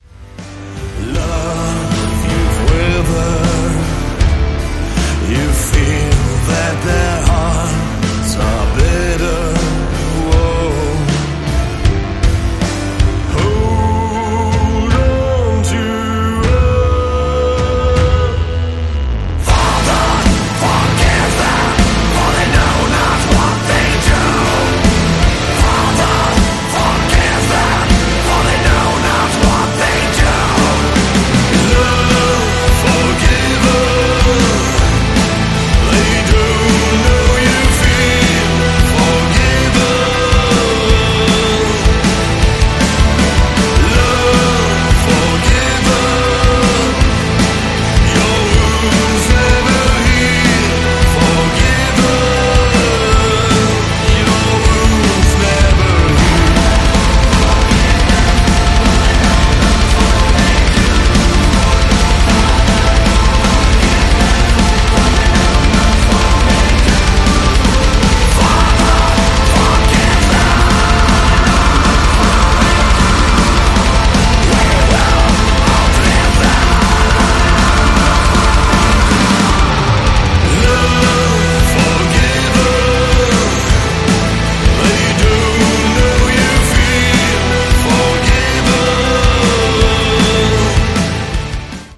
Haunting
Gothic Metal is very atmospheric, very contrasted, and has a great sense of dark aesthetics. Gothic Metal relies heavily on bass guitar played in lower tones in combination with keyboards to set the mood of the song. To add further contrast dual vocalists are frequently employed: One female with soprano or operatic vocals, and the other male using Thrash, Death, or Black Metal styled vocals. This combination of singing styles is commonly referred to as "Beauty and the Beast" vocals.
Thus the combined effect gives Gothic Metal its characteristic haunting quality.
gothic2.mp3